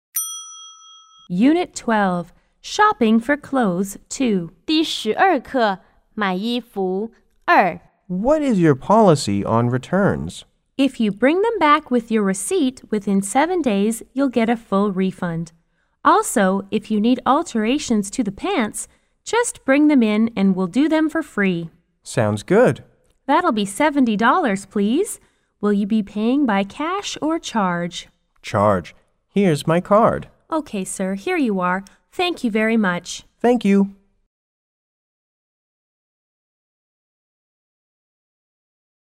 C= Customer S= Salesperson